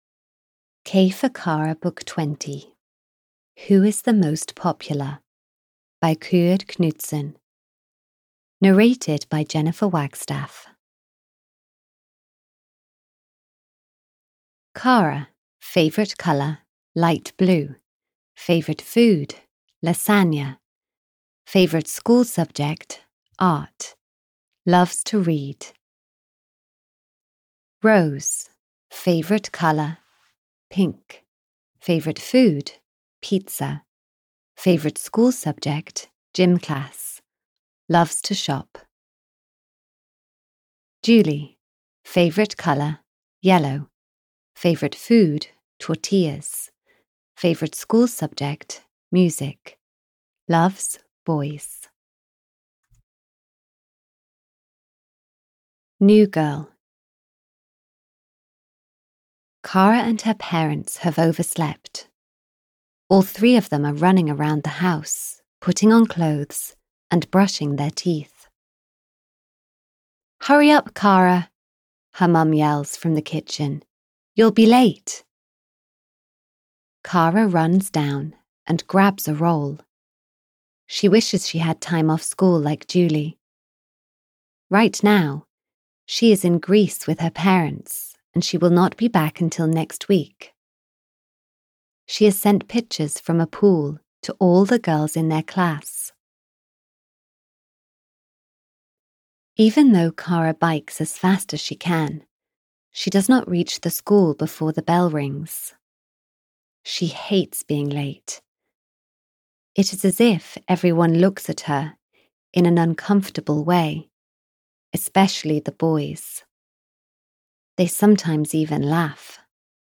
Audio knihaK for Kara 20 - Who is the Most Popular? (EN)
Ukázka z knihy